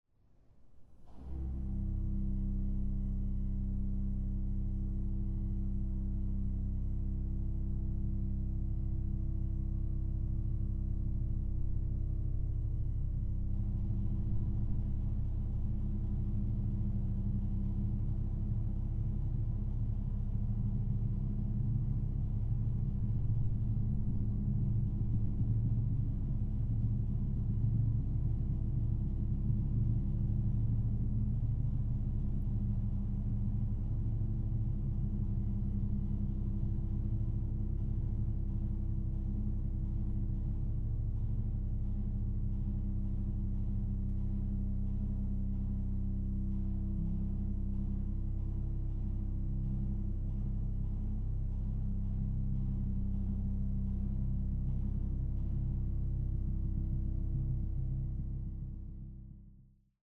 Orgel
Aufnahme: Het Orgelpark, Amsterdam, 2023